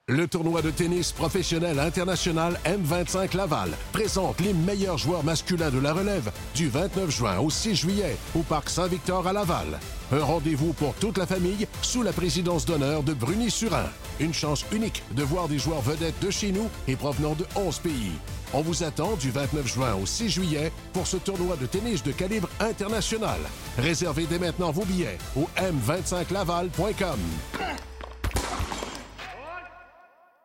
Capsule : Annonce radio 98,5 FM